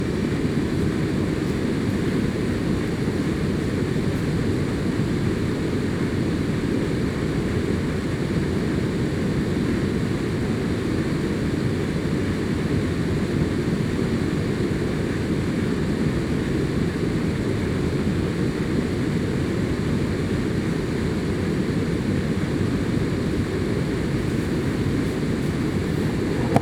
environnement_01.wav